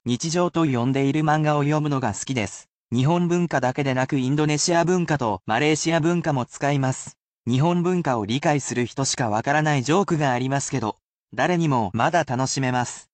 [basic polite speech]